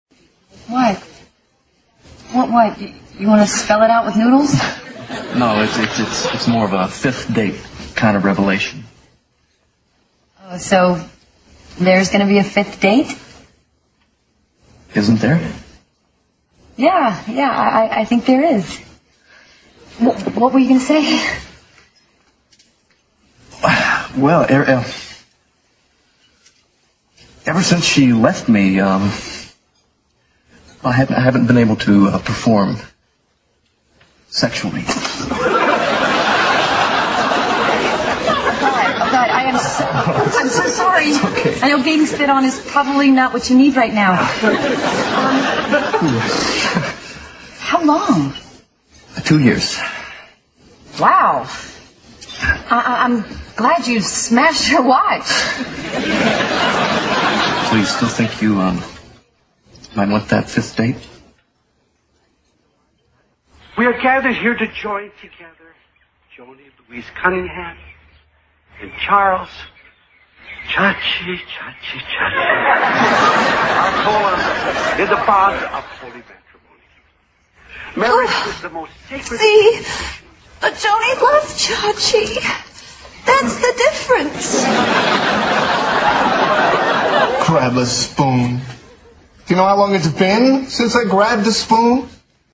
在线英语听力室老友记精校版第1季 第6期:莫妮卡的新室友(6)的听力文件下载, 《老友记精校版》是美国乃至全世界最受欢迎的情景喜剧，一共拍摄了10季，以其幽默的对白和与现实生活的贴近吸引了无数的观众，精校版栏目搭配高音质音频与同步双语字幕，是练习提升英语听力水平，积累英语知识的好帮手。